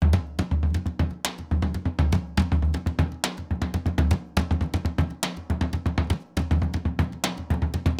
Surdo Merengue 120_2.wav